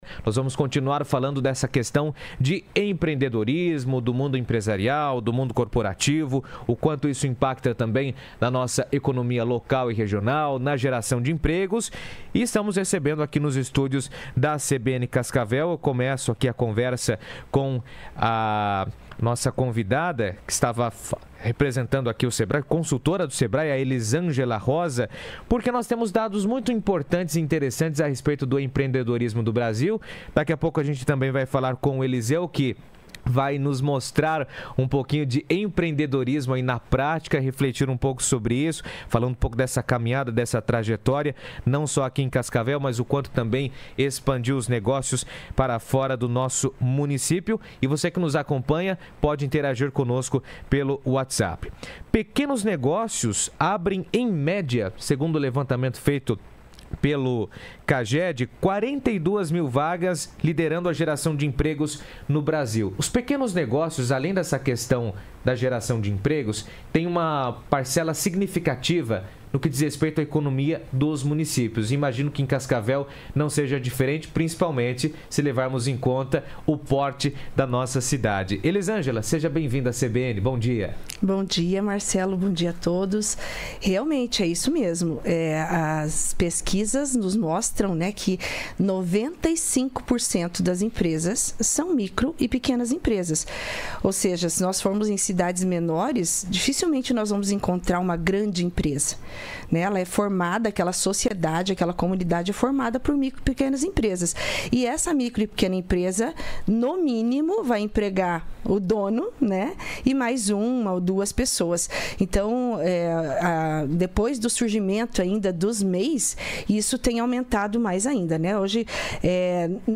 Nesta quarta, no estúdio da CBN Cascavel,